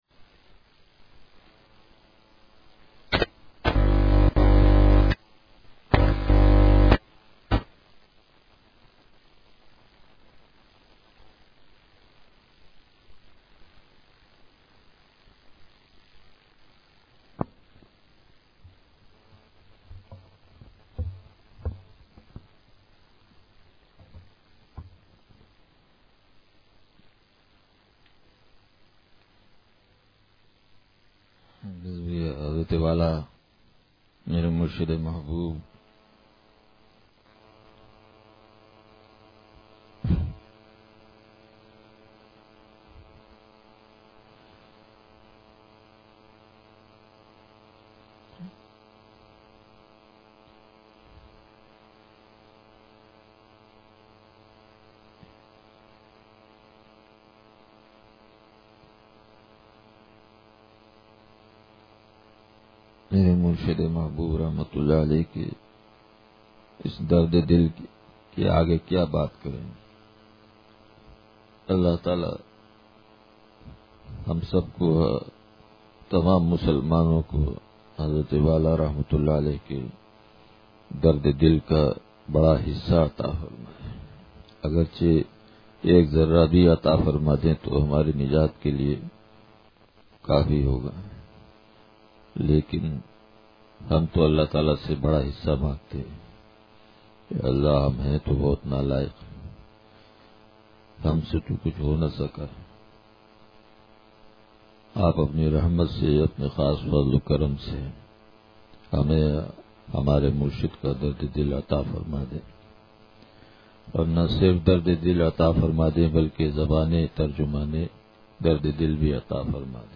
اصلاحی بیان – اتوار